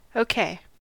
OK (/ˌˈk/
En-us-okay.ogg.mp3